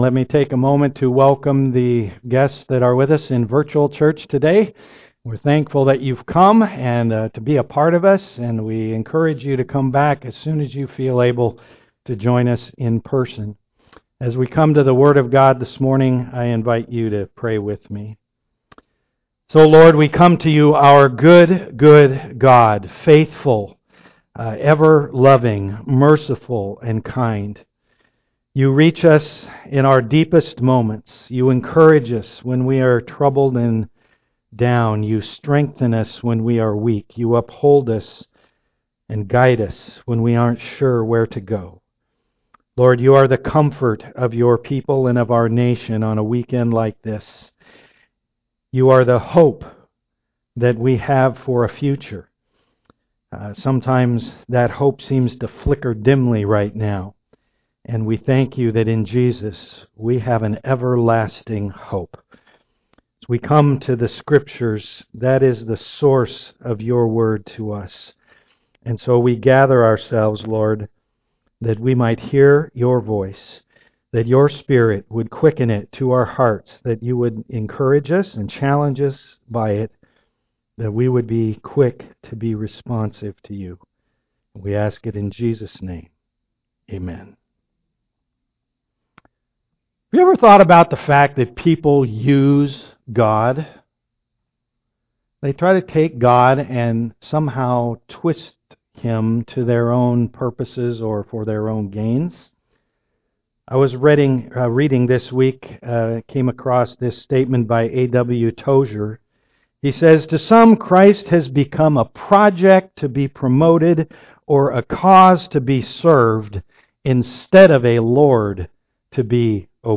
Acts 24:1-27 Service Type: am worship Life is often unfair.